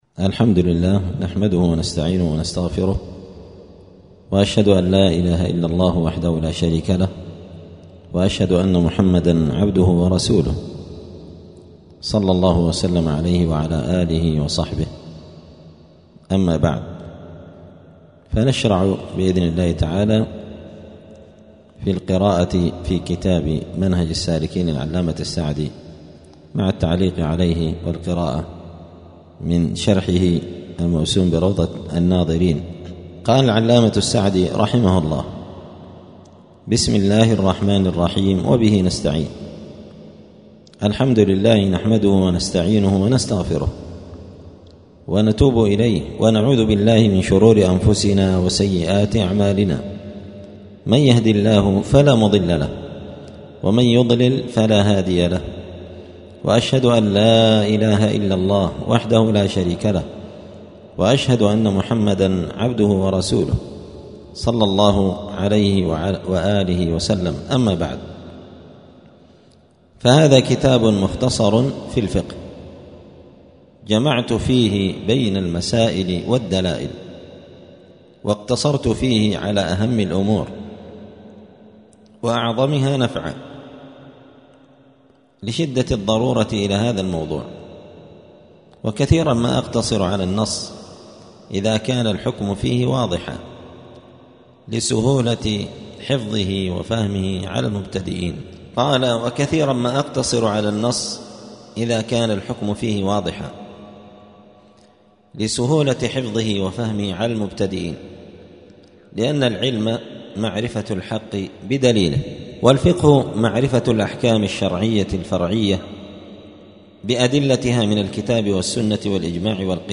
الأثنين 4 ربيع الثاني 1446 هــــ | الدروس، دروس الفقة و اصوله، كتاب روضة الناظرين شرح منهج السالكين | شارك بتعليقك | 81 المشاهدات
دار الحديث السلفية بمسجد الفرقان قشن المهرة اليمن